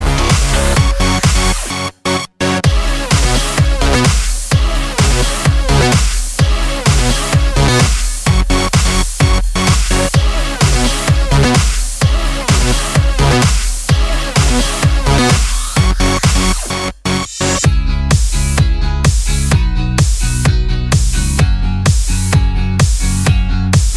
For Solo Singer Comedy/Novelty 3:28 Buy £1.50